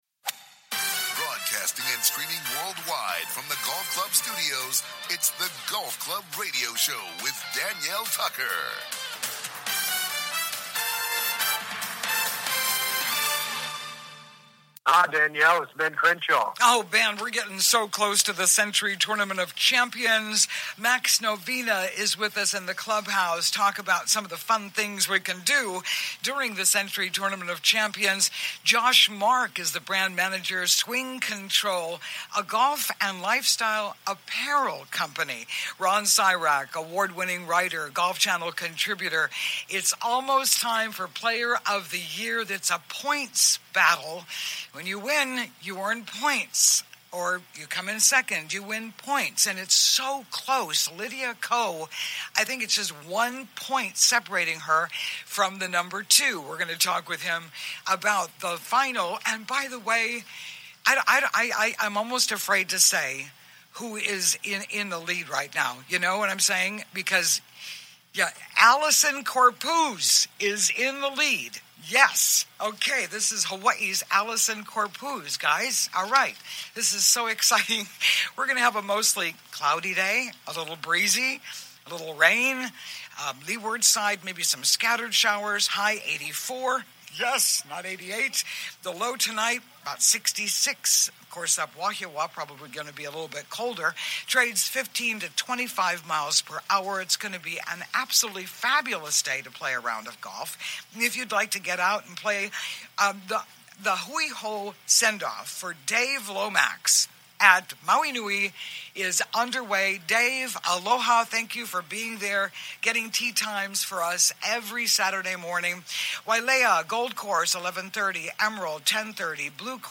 COMING TO YOU LIVE FROM THE GOLF CLUB STUDIOS ON LOVELY OAHU’s SOUTH SHORE